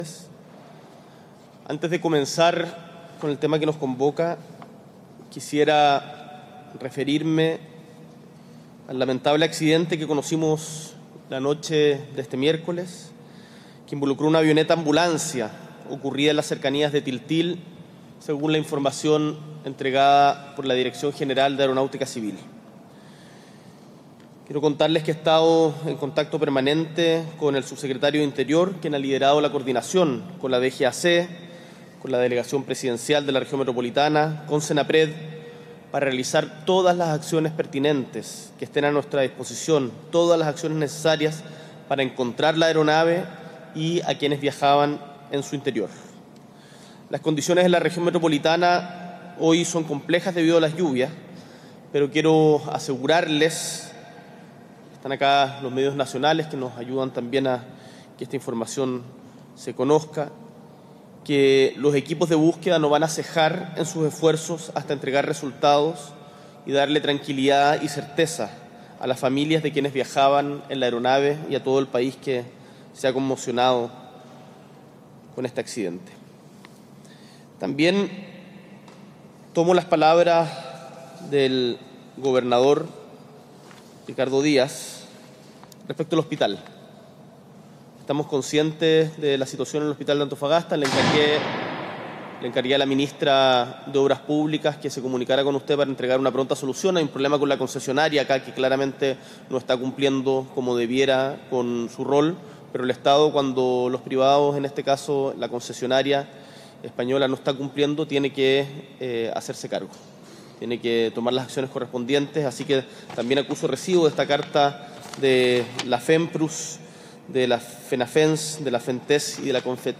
Audio Discurso